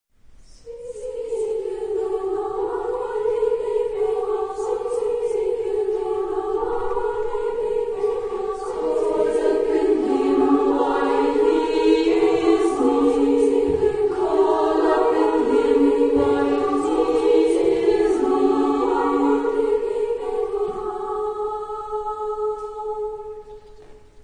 Mädchenchor